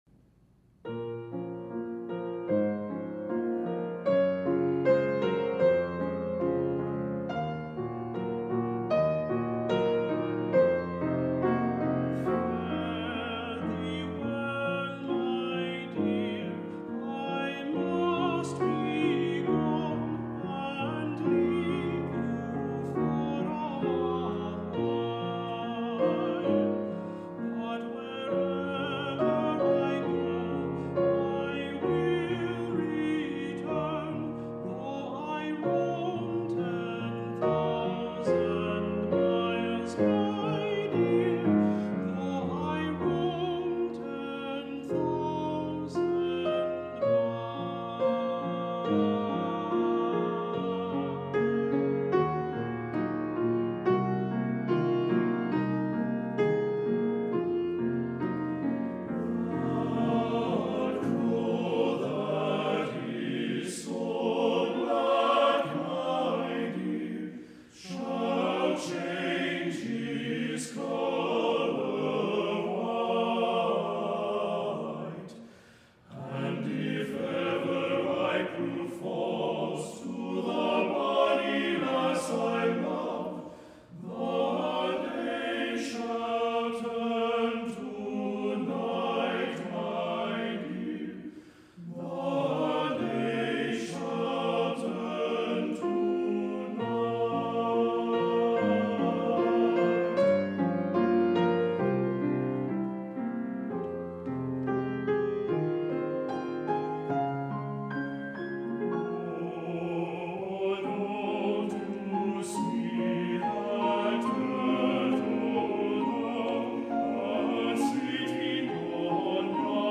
Voicing: TTB (or TBB)